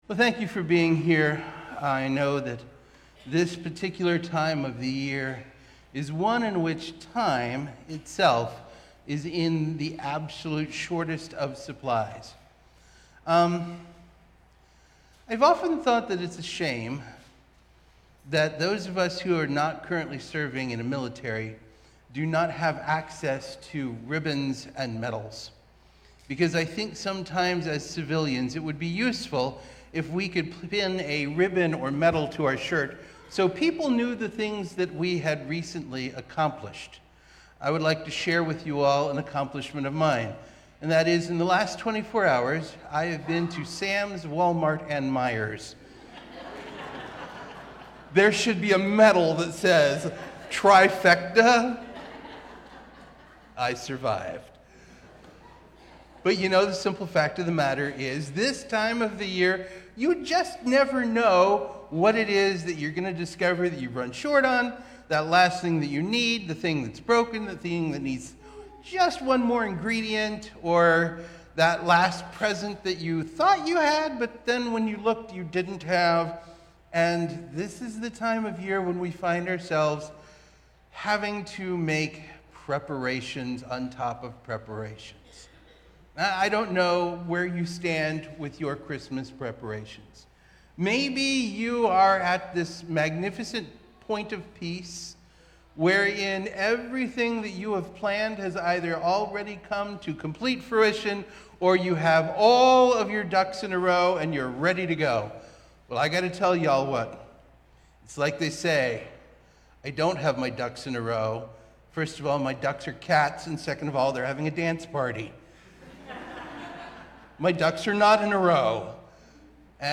Christmas Eve 2024